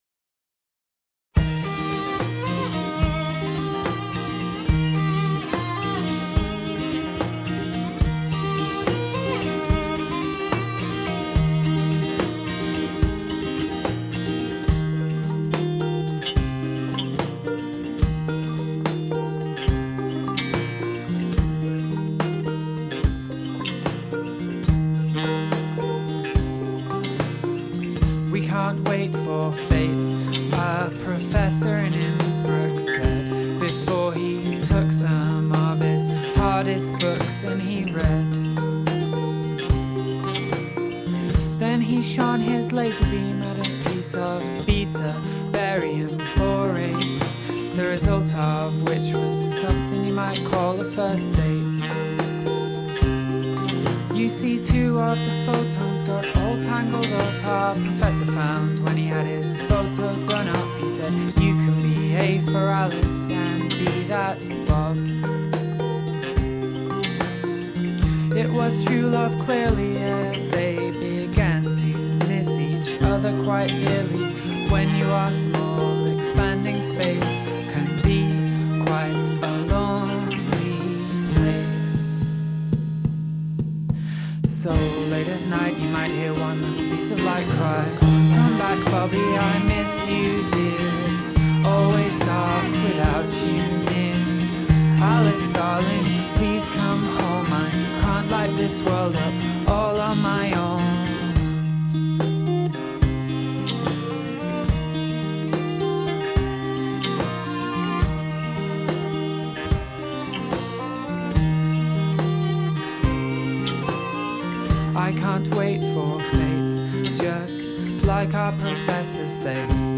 violinist